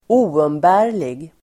Uttal: [²'o:umbä:r_lig]